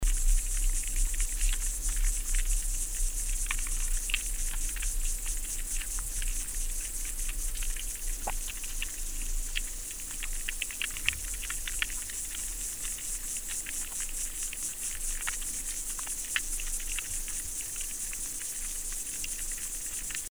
He’s a little guy with a big chirping voice.
3-Midnight-river-insect-sounds.mp3